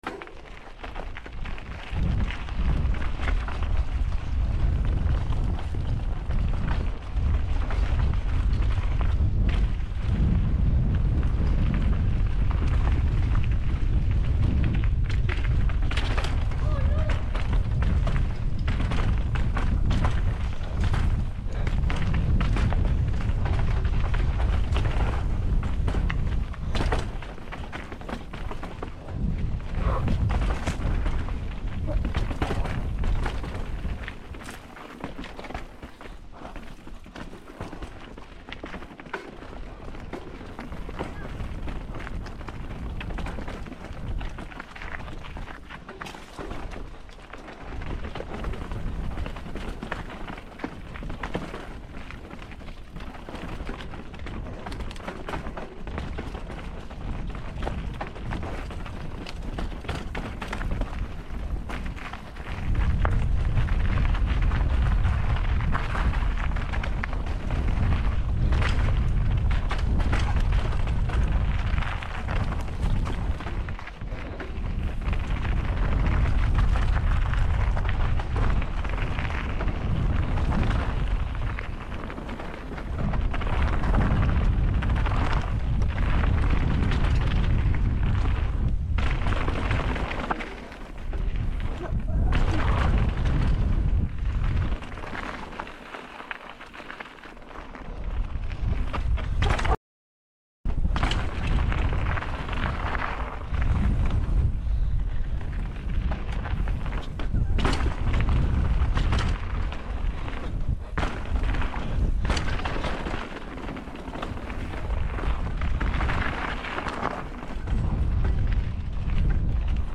hey sound effects free download